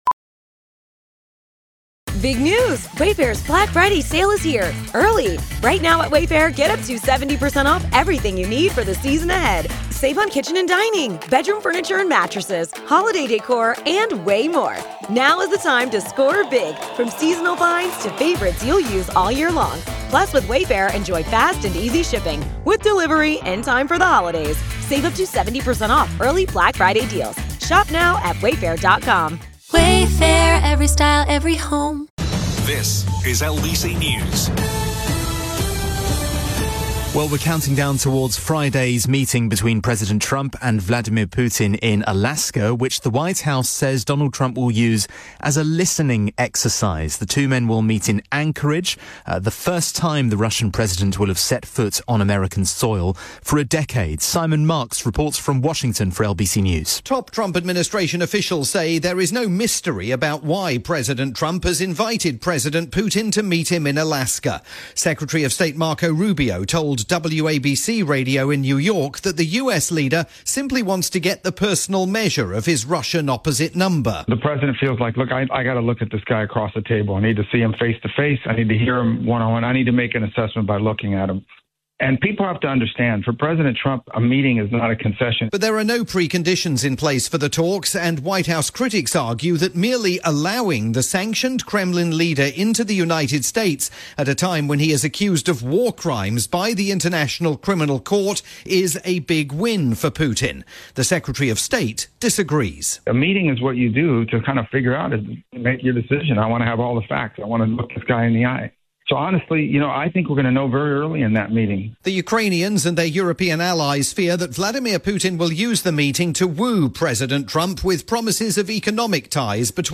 report for breakfast news on LBC News